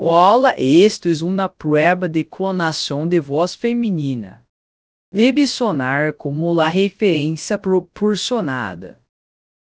dub_female_clone_es.wav